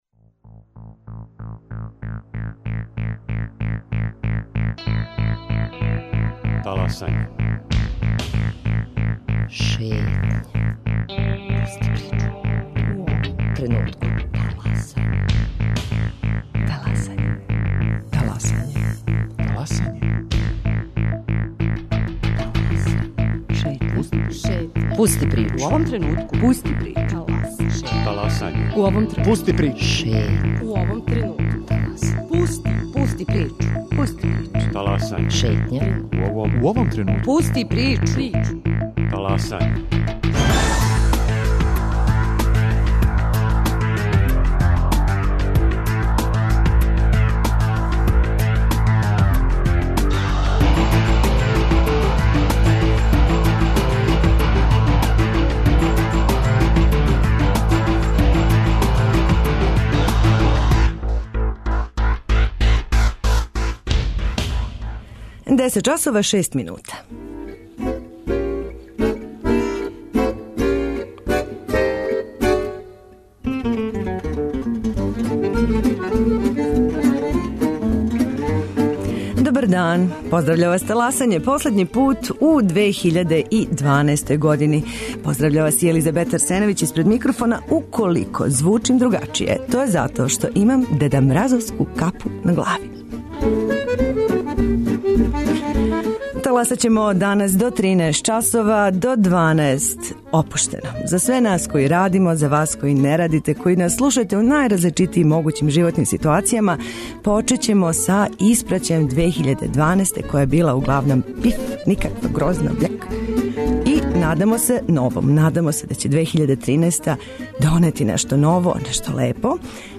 У првом сату 'Таласања', уз много добре музике и неколико рубрика које нас воде у различите делове земље, почињемо да испраћамо стару годину.